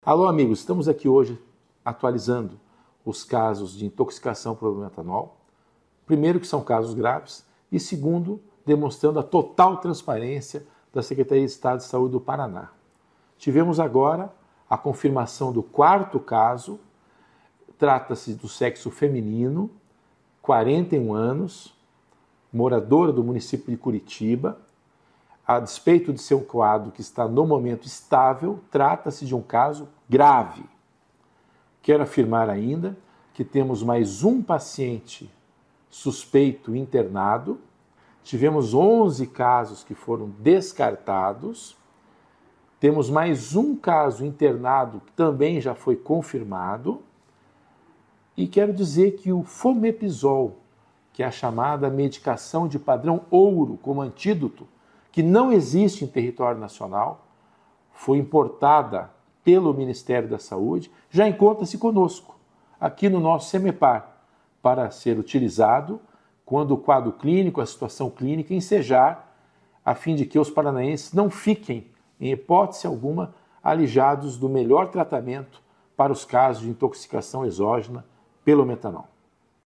Sonora do secretário da Saúde em exercício, César Neves, sobre a atualização dos casos de intoxicação por metanol no Paraná